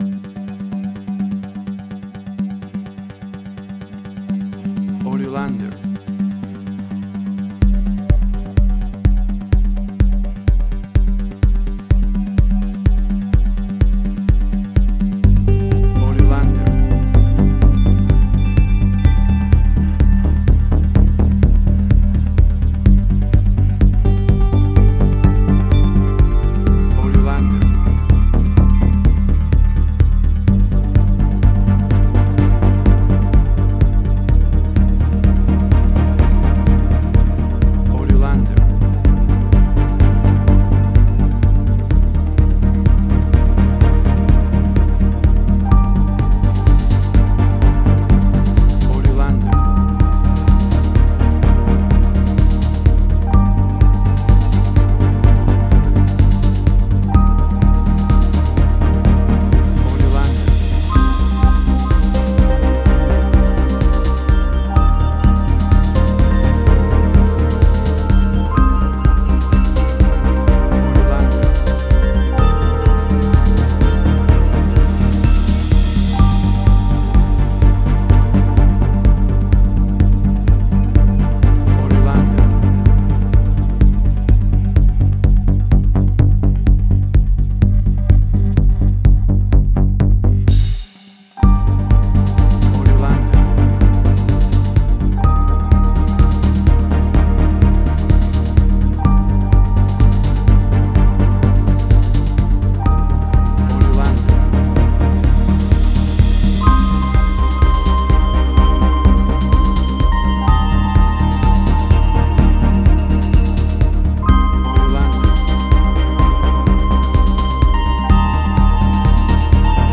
Future Retro Wave Similar Stranger Things New Wave.
Tempo (BPM): 126